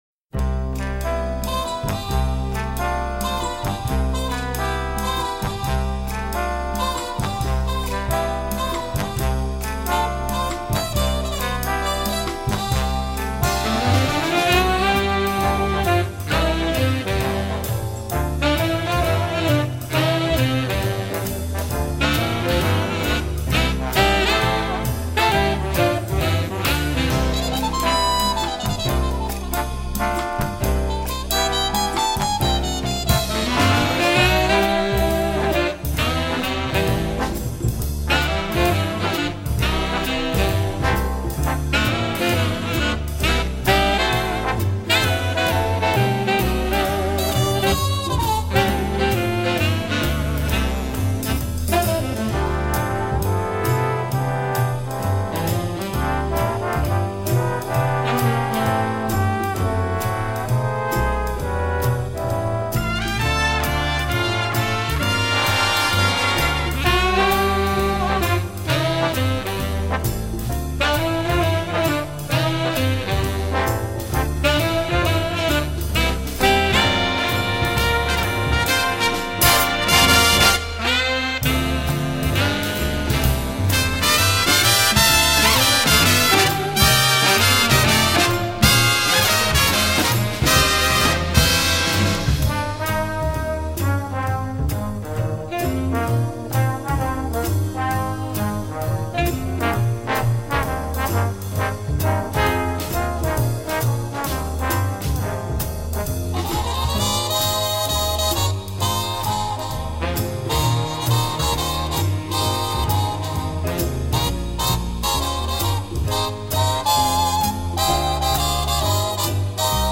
sax 1st 3rd 2nd 4th 5th
trp 1st 2nd 3rd 4th
trb 1st 2nd 3rd 4th
rythm Guitar Piano Bass Drums